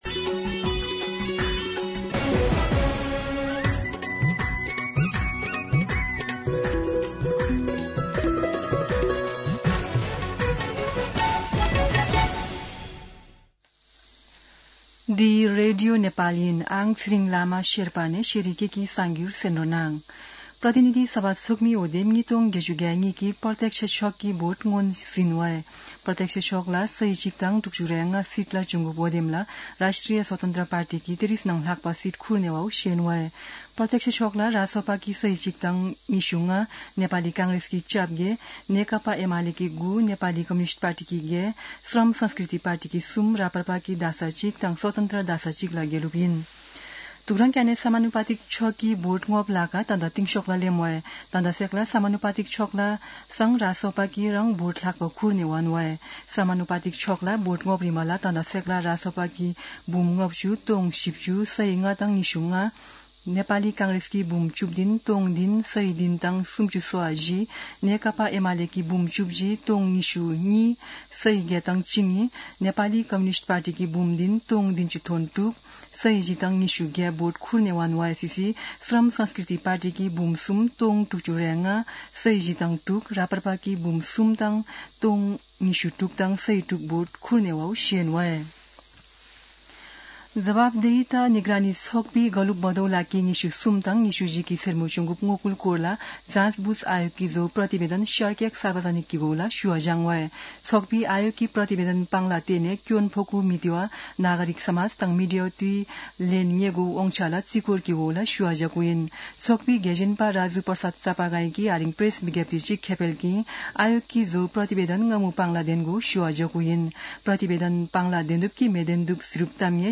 शेर्पा भाषाको समाचार : २६ फागुन , २०८२